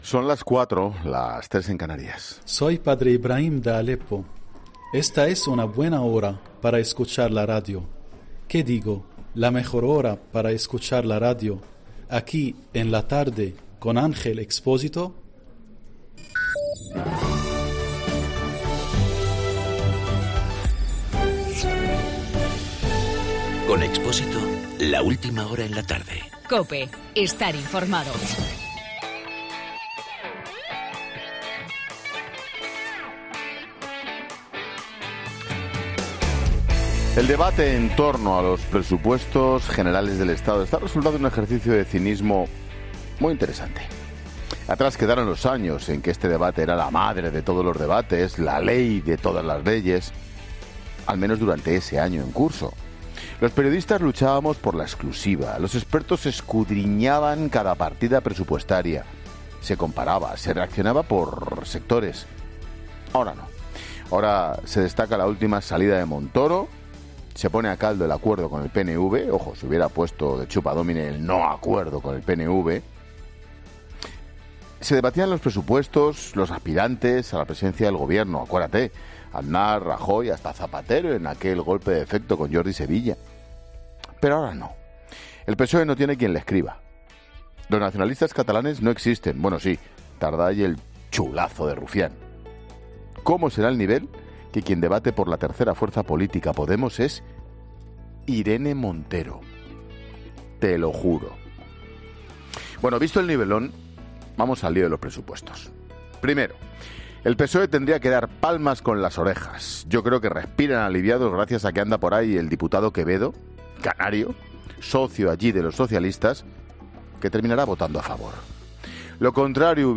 AUDIO: Monólogo 16h.